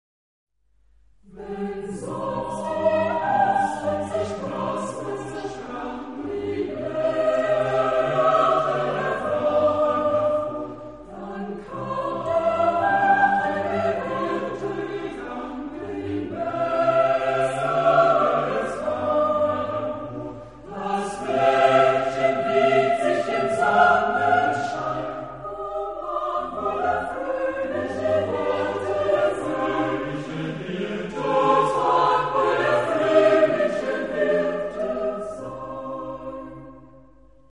Genre-Style-Form: Secular ; Romantic
Type of Choir: SATB  (4 mixed voices )
Tonality: C minor